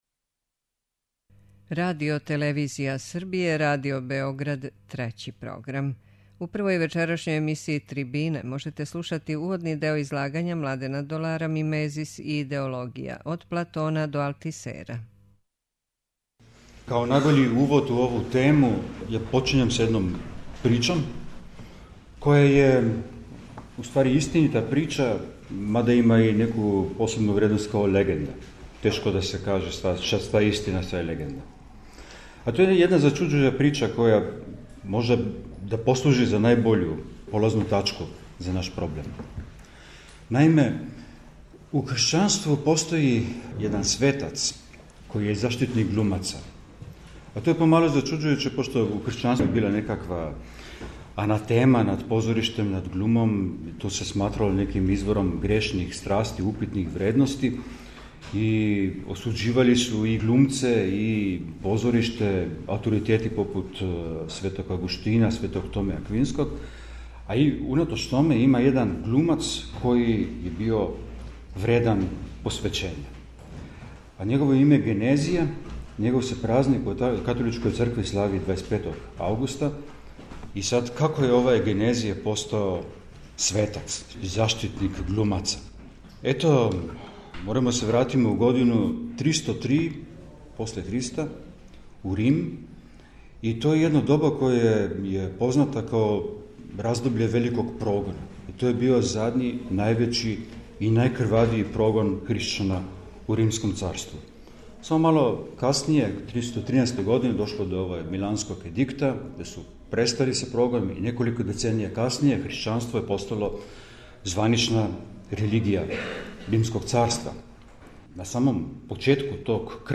Трибине
Снимак је забележен 3. октобра у Институту за филозофију и друштвену теорију у Београду.